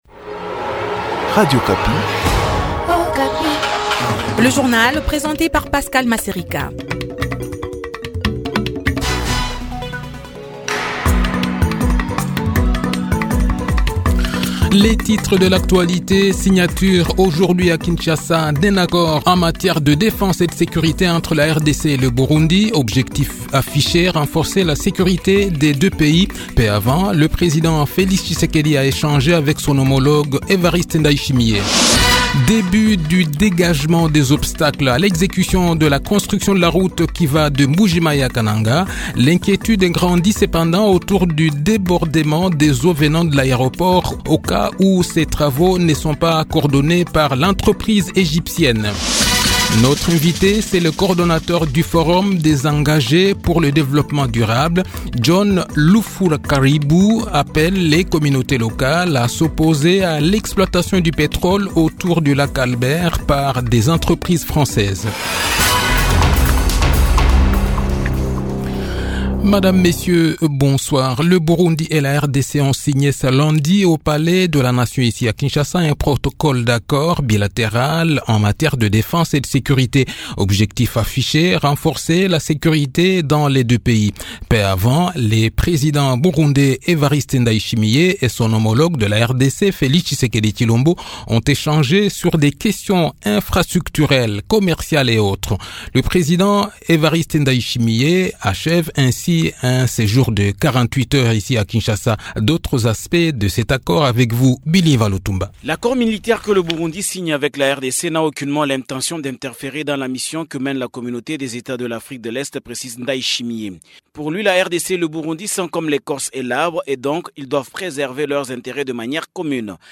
Le journal de 18 h, 28 Aout 2023